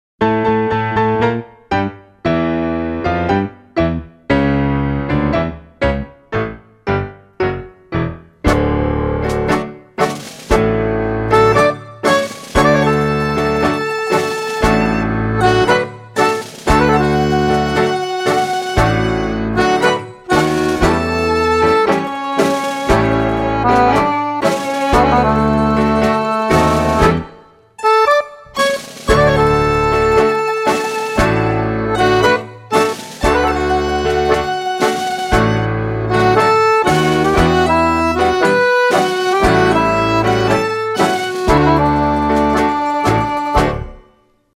Music for Scottish Country and Old Tyme Dancing
Tango